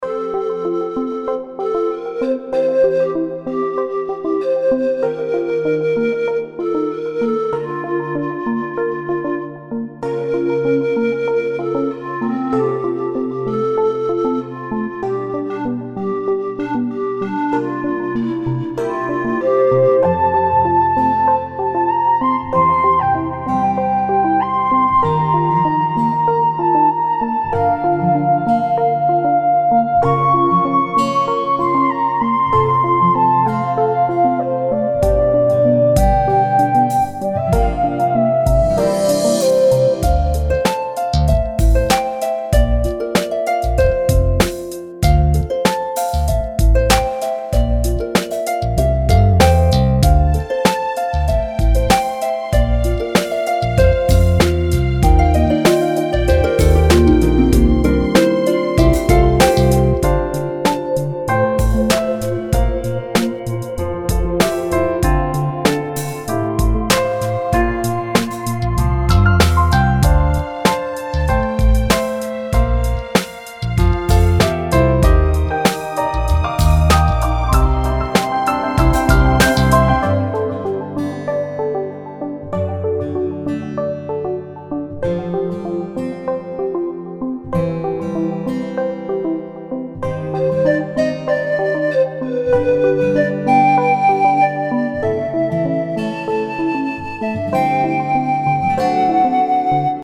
轻松欢快的节奏，让人也变得愉悦起来~每一个音符都是如此的美妙。